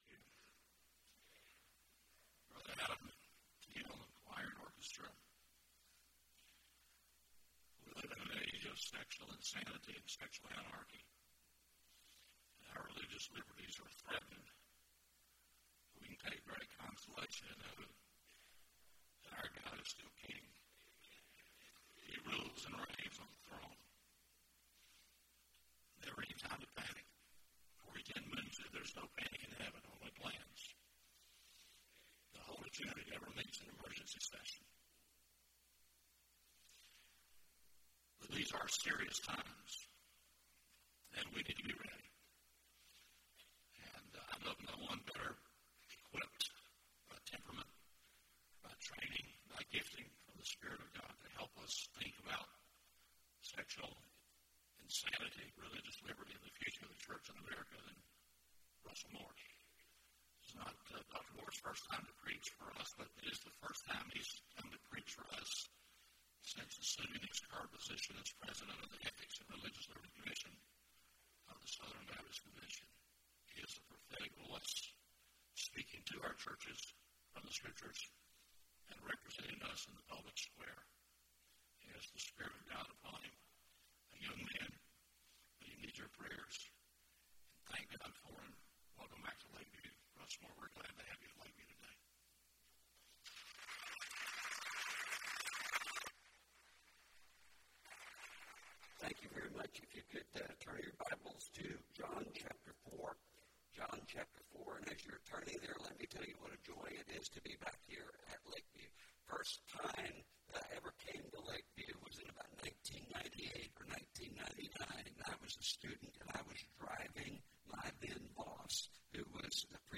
Guest Speaker – Russell Moore (Sexual Insanity, Religious Liberty & the Future of Church in America)